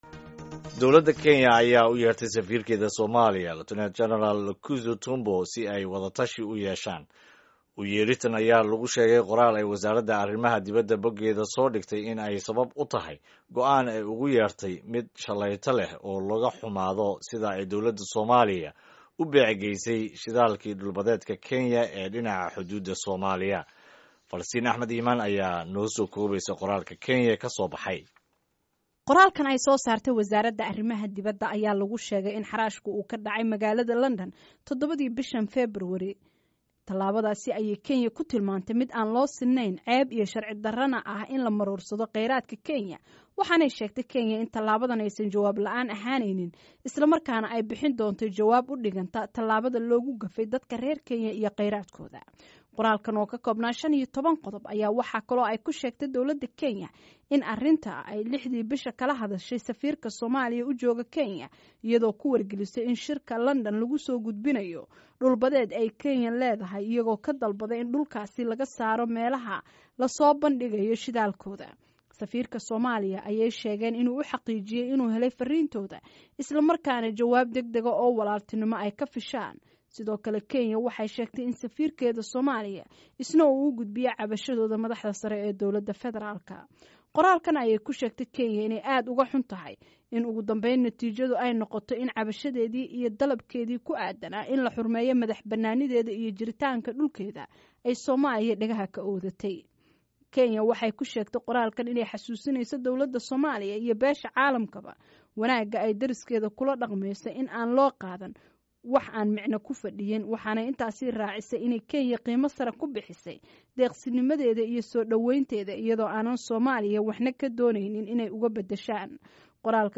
Dhageyso aragtida qaar ka mid ah dadka ku dhaqan Somalia iyo W. Bari Kenya ay ka qabaan talaabada Kenya ee eryidda danjiraha Somalia ee Nairobi. Ka dibna dhageyso wareysi ku saabsan ismaan dhaafka labada waddan.
Warbixinta Kenya